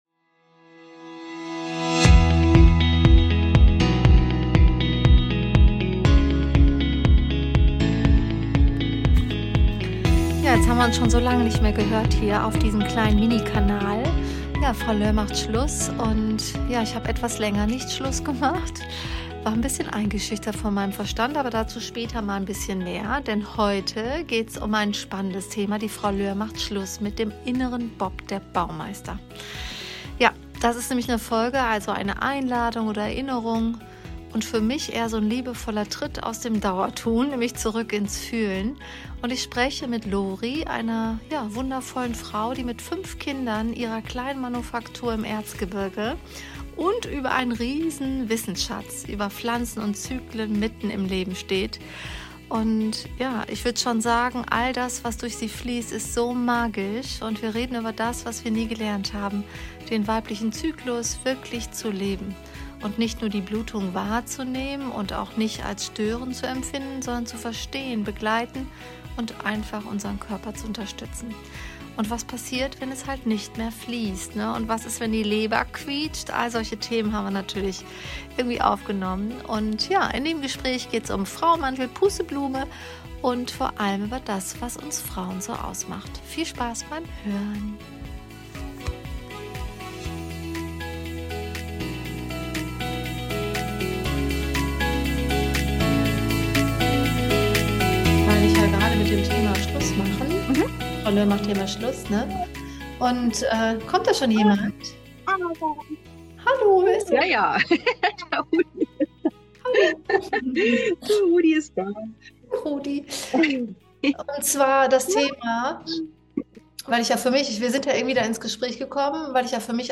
Ein Gespräch über Frauenmantel, Pusteblume, Schafgarbe – und vor allem über das, was uns Frauen ausmacht: die Fähigkeit, loszulassen.